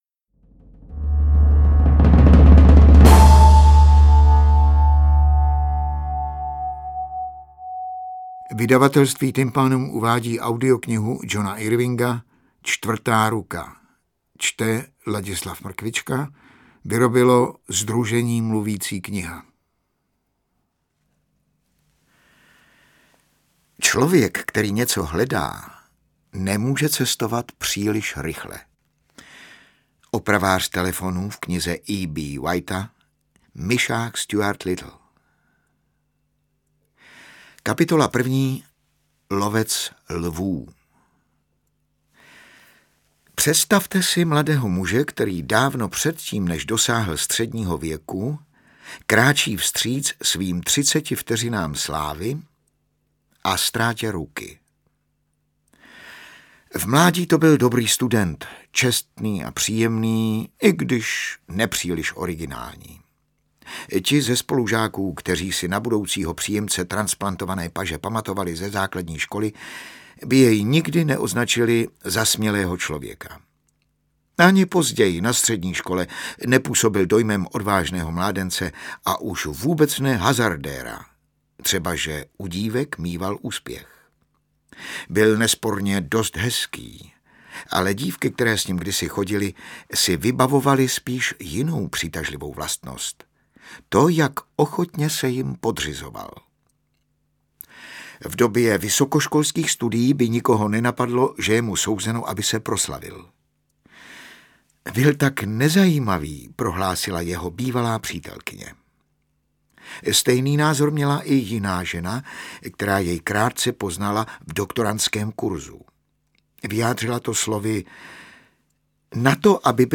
Interpret:  Ladislav Mrkvička
AudioKniha ke stažení, 74 x mp3, délka 12 hod. 53 min., velikost 1057,1 MB, česky